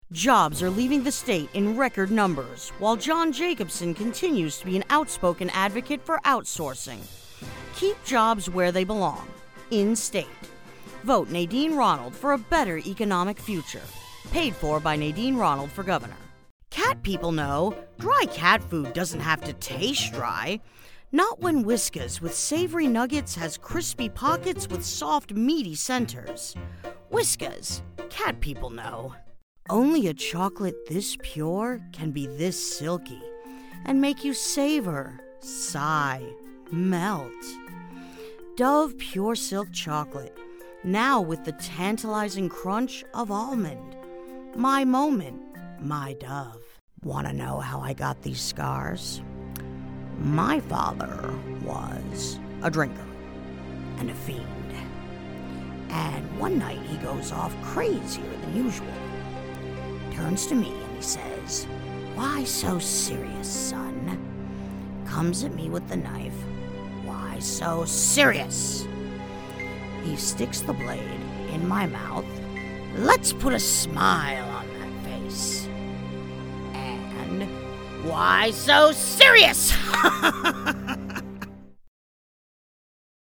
Commercial Demo
North American (native); New England, Texan, New York